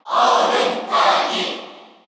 Category: Crowd cheers (SSBU) You cannot overwrite this file.
Ice_Climbers_Cheer_Korean_SSBU.ogg